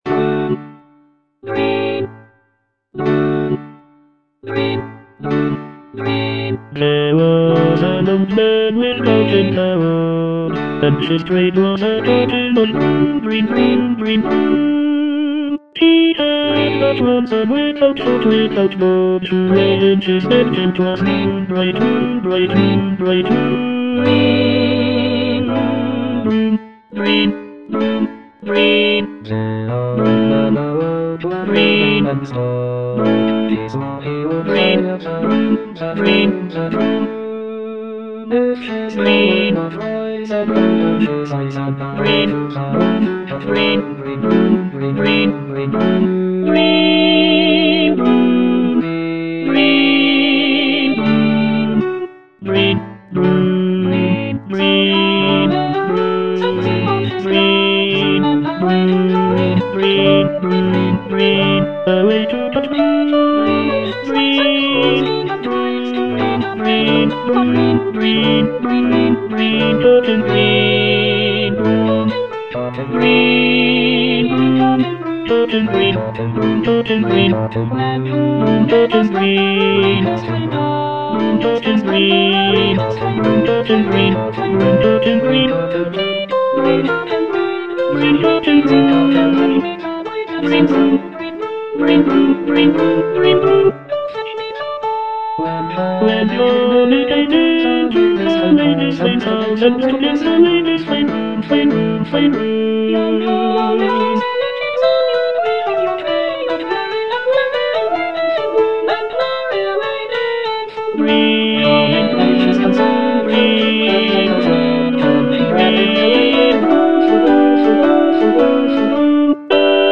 Tenor II (Emphasised voice and other voices)
folk song